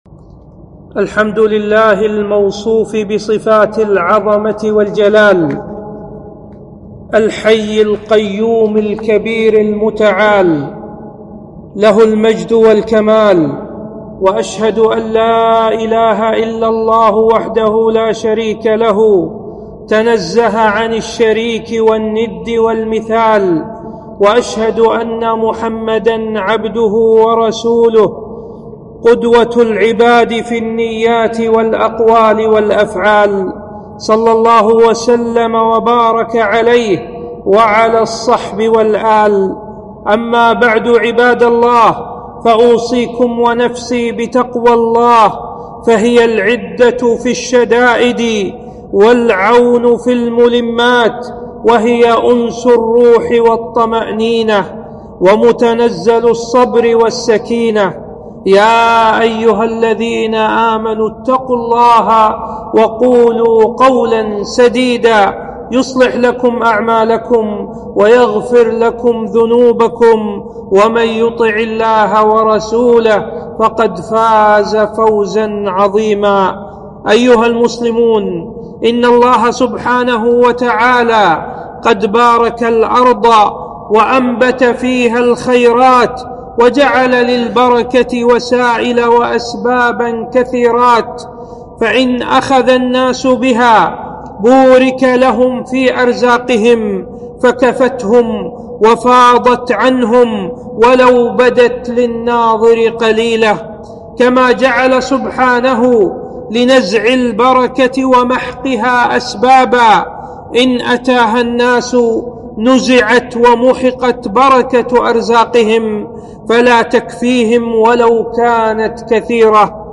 خطبة - أسباب نزع البركة